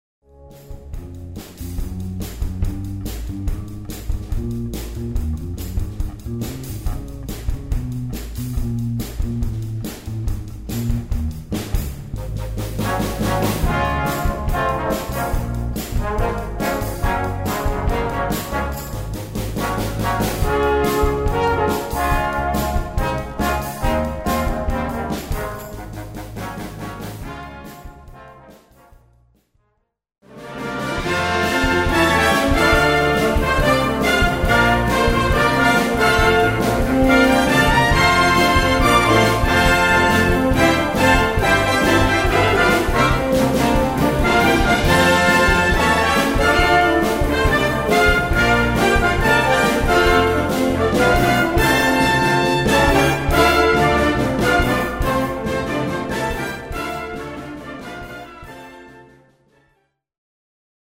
C Besetzung: Blasorchester Zu hören auf